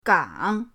gang3.mp3